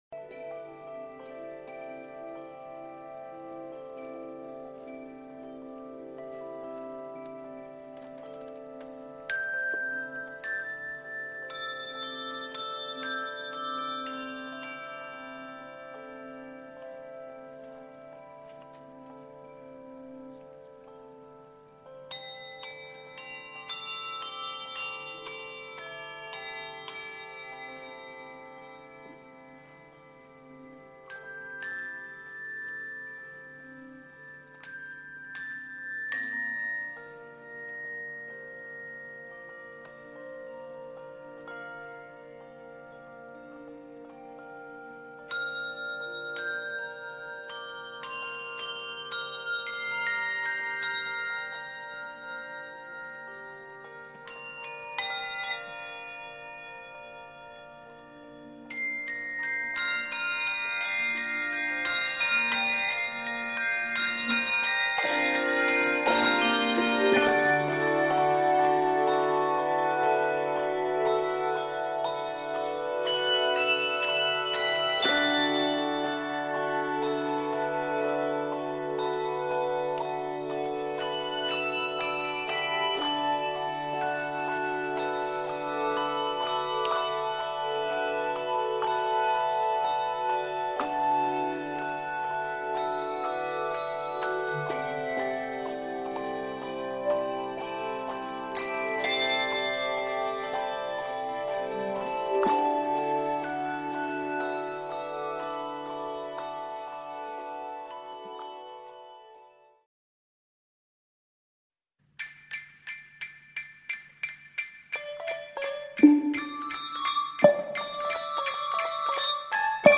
Voicing: Handbells 4-6 Octave